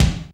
HR16B   BD 2.wav